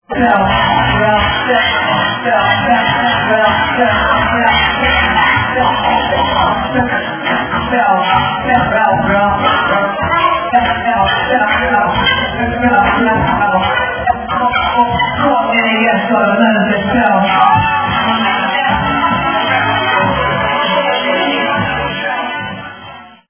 scratchnas.mp3